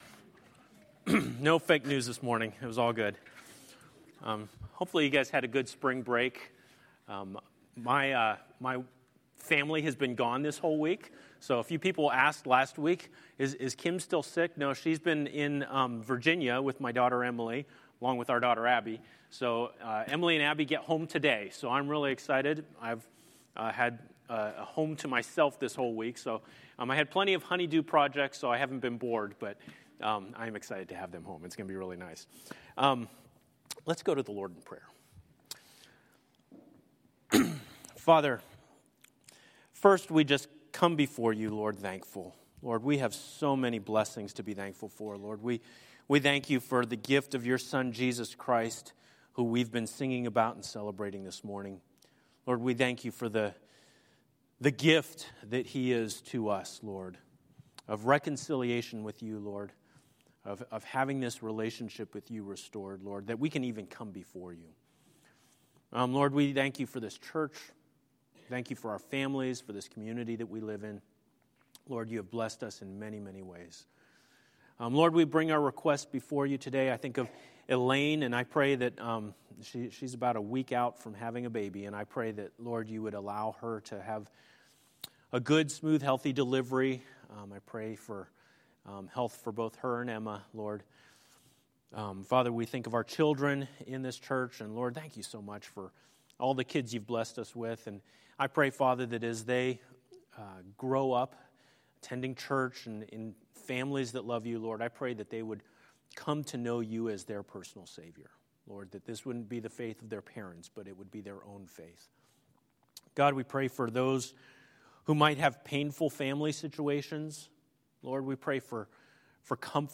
A message from the series "Battle Lines."